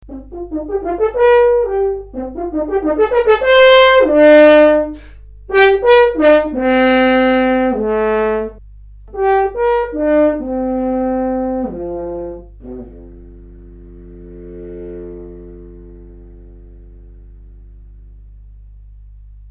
Horn Workshop July 05